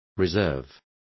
Complete with pronunciation of the translation of reserving.